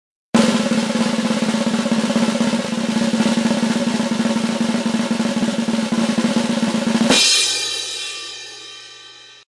Fc Redoble De Tambores Sound Button - Free Download & Play